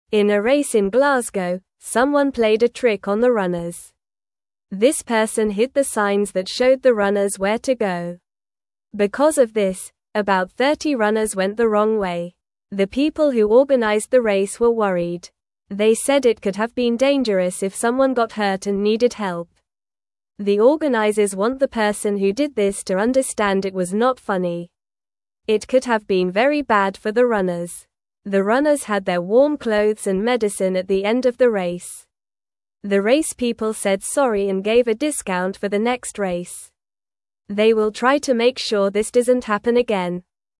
Normal
English-Newsroom-Beginner-NORMAL-Reading-Trickster-Confuses-Runners-in-Glasgow-Race.mp3